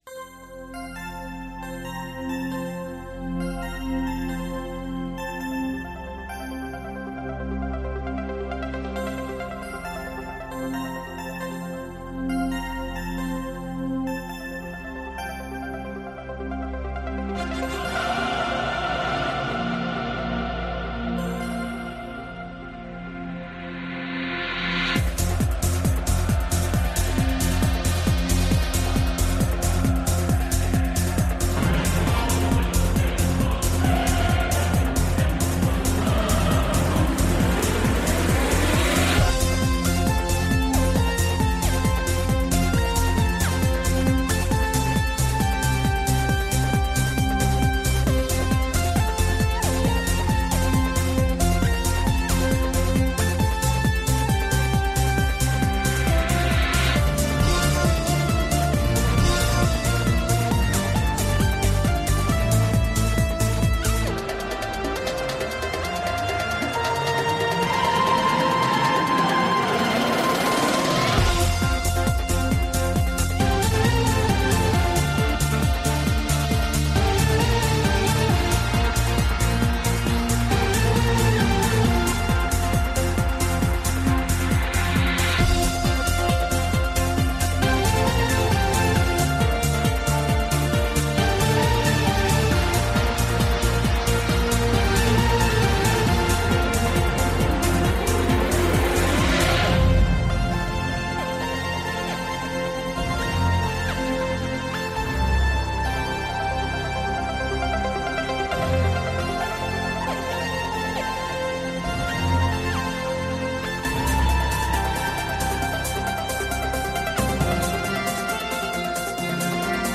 Elkarrizketa